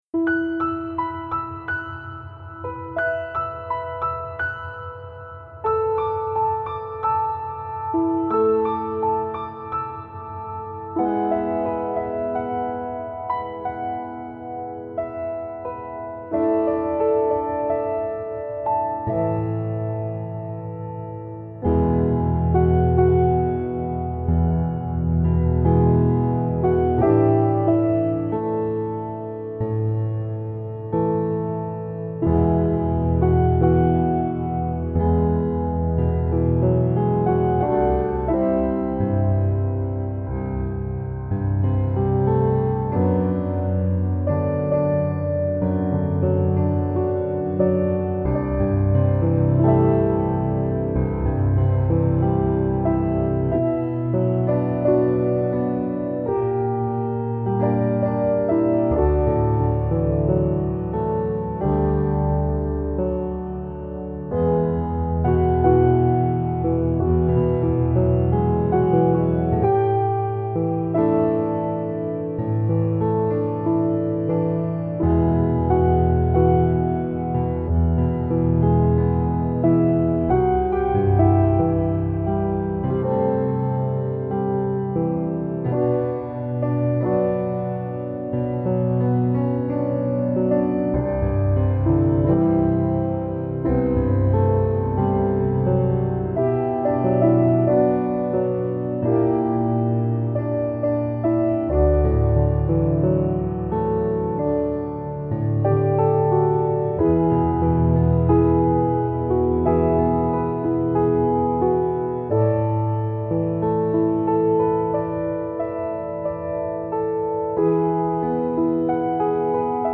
HEALING PIANO MELODIES – PART 3
This medley consists of piano solos for 15 of my songs.
Alone – Piano Melody